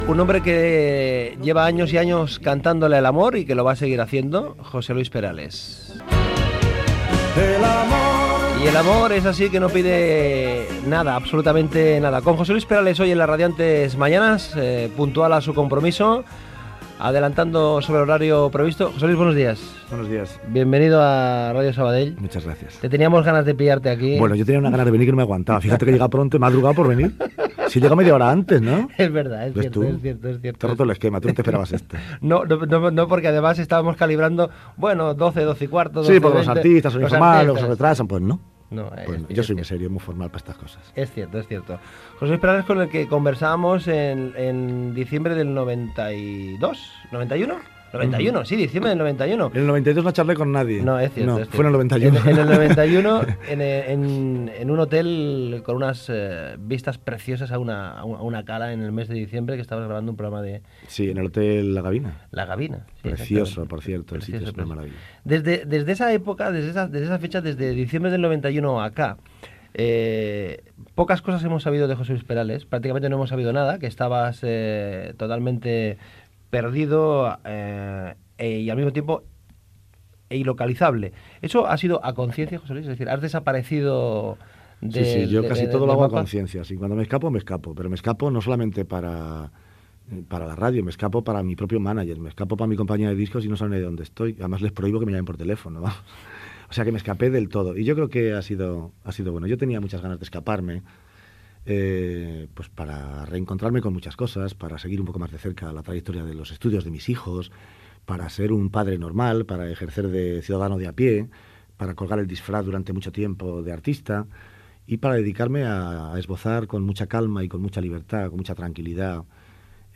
Fragment entrevista al cantautor José Luis Perales
Entreteniment